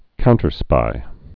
(kountər-spī)